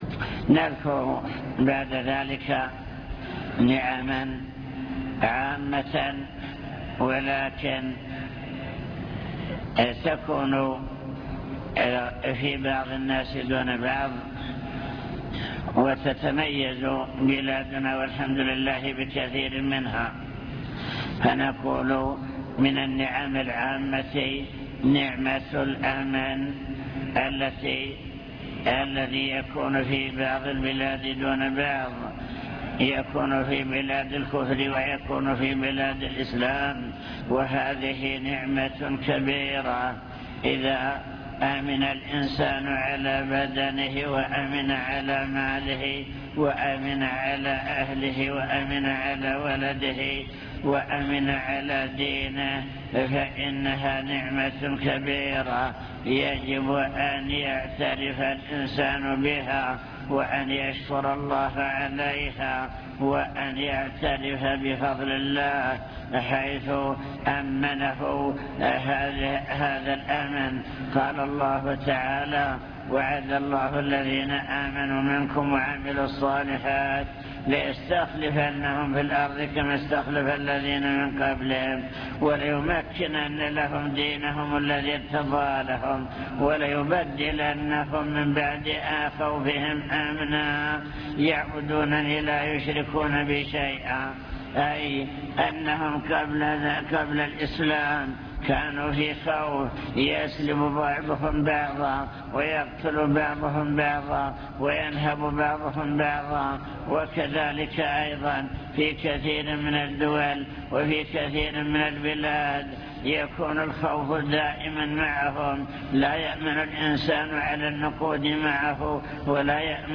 المكتبة الصوتية  تسجيلات - محاضرات ودروس  محاضرة بعنوان شكر النعم (1) ذكر نماذج لنعم الله تعالى العامة وكيفية شكرها